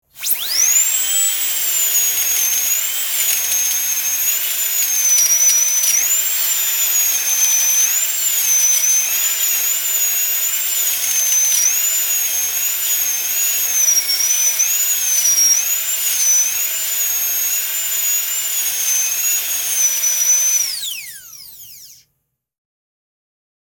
#5 – The sound and smell of dentists’ drills doing their work.